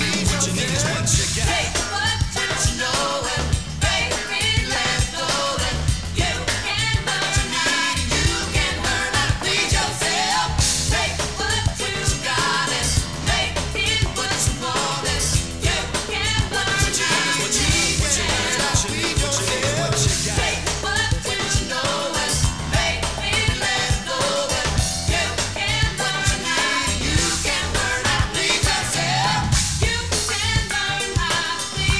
8 bit mono
From the Demo Tape